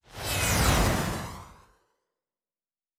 Weapon 11 Shoot 1 (Rocket Launcher).wav